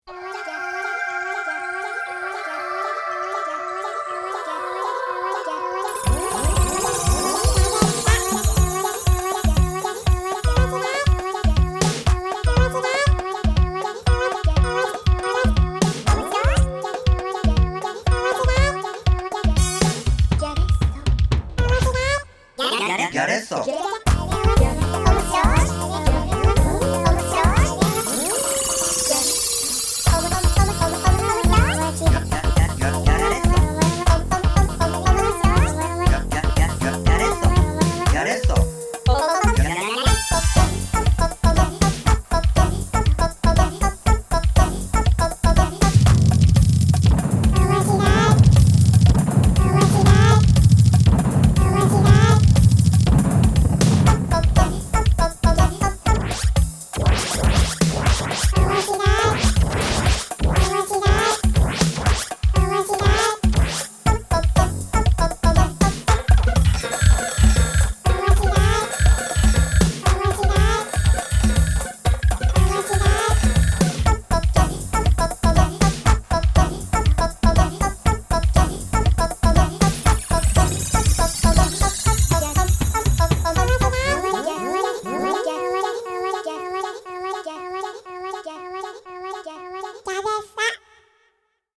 コマーシャル音楽
個性豊かで、独自の世界を演出しています。